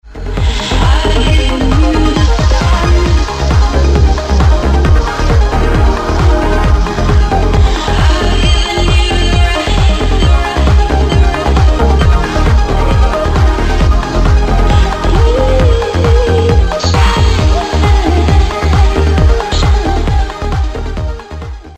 It's Trance...
MP3 of the chorus is posted
She says: